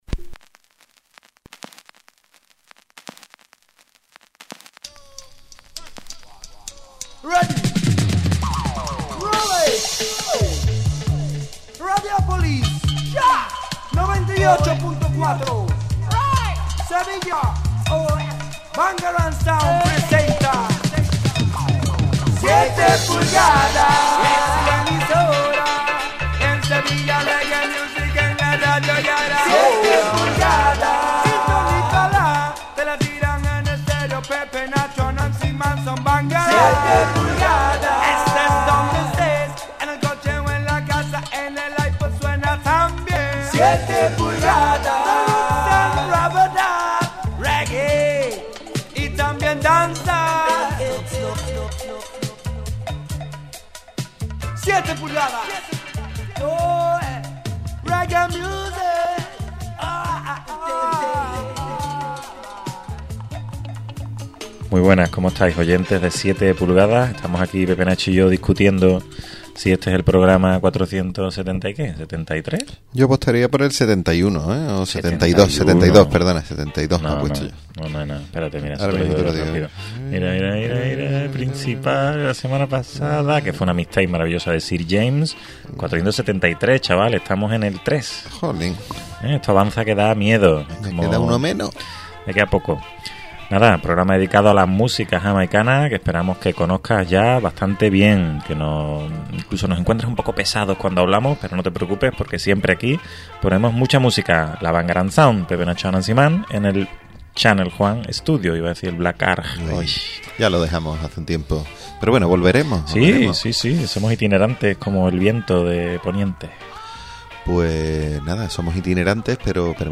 Presentado y dirigido por la Bangarang Sound y grabado en Channel Juan Studio.